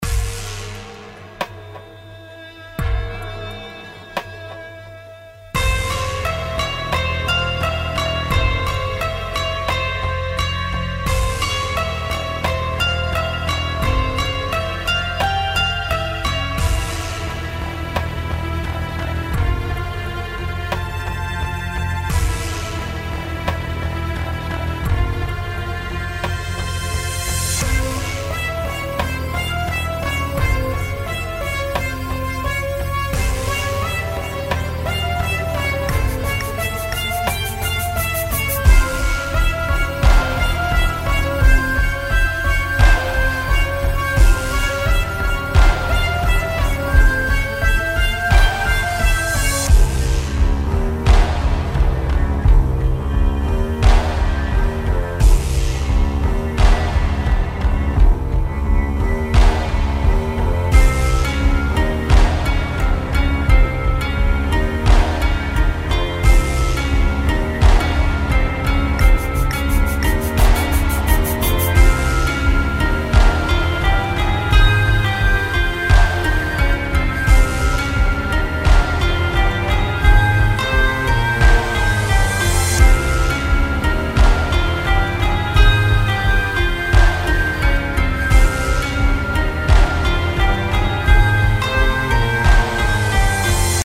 • スローなテンポ（84BPM)が、深く重たい空気感を演出
• アナログ感のあるパッド音源＋民族打楽器が静かに広がる
• リバーブとEQ処理で「深さ・奥行き・乾き」を表現
フリーBGM 孤独 砂漠 ゲームBGM アンビエント ファンタジー ミステリー 神秘 探検 静寂